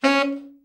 TENOR SN  18.wav